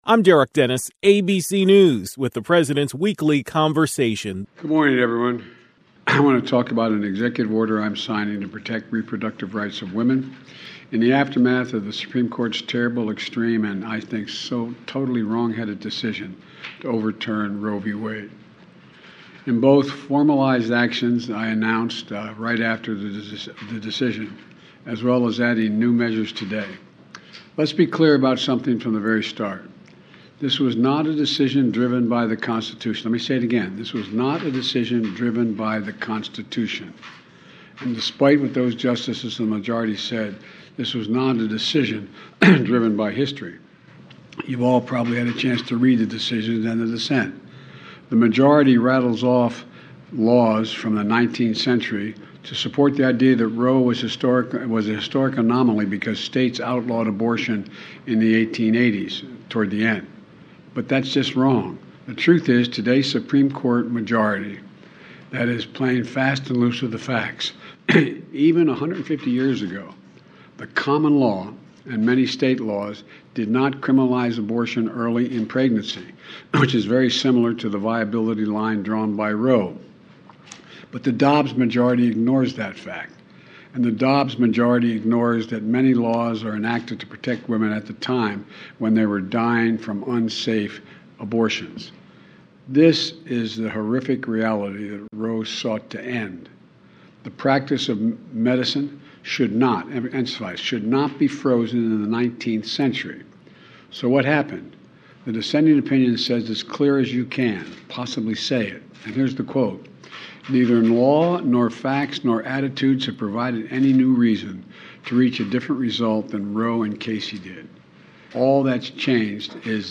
President Biden delivered remarks before signing an Executive Order ‘Protecting Access to Reproductive Health Care Services’.